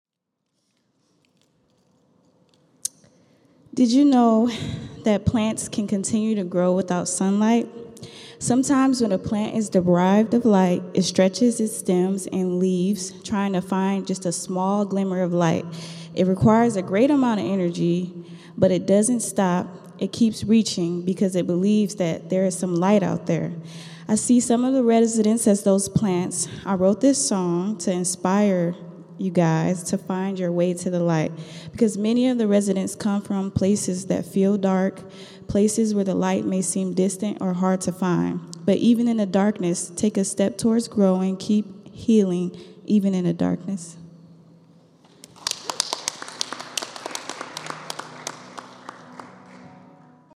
At our last concert, she read a poem